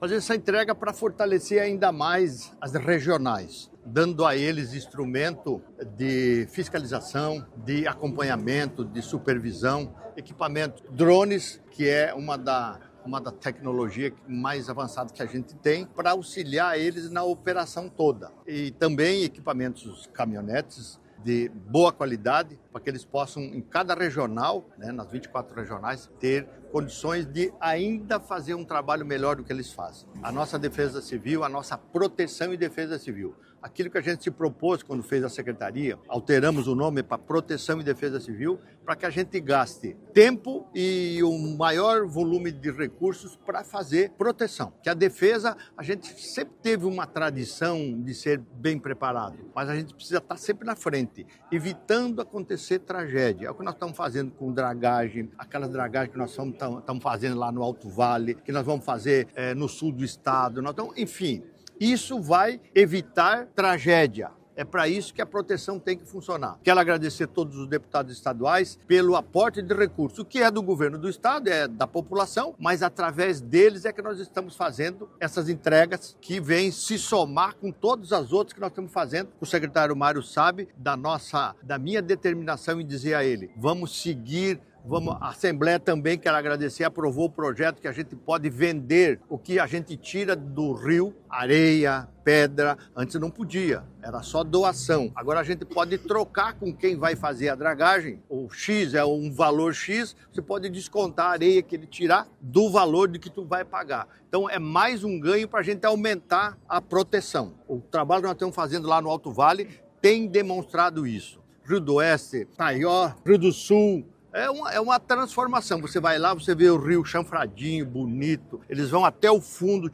O governador Jorginho Mello destacou a importância das ações prévias, e não somente em situações extremas:
O secretário de Estado da Proteção e Defesa Civil, Mário Hildebrandt, destacou que investir em tecnologia é investir em vidas:
O presidente da Federação Catarinense de Municípios (Fecam) e prefeito de Florianópolis, Topázio Neto, destacou a importância de um sistema de prevenção e defesa civil integrado: